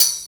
35 HAT+TMB-L.wav